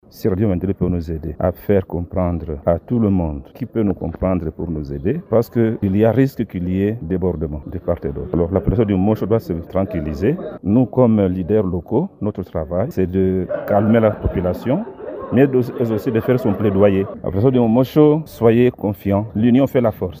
Ils l’ont signifié à l’occasion d’une émission publique organisée à Mumosho par Radio Maendeleo Dimanche 8 décembre 2024.